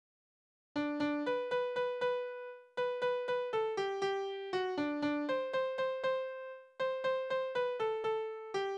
Tonart: G-Dur
Taktart: 4/8
Tonumfang: kleine Septime
Besetzung: vokal
Anmerkung: Vortragsbezeichnung: Polka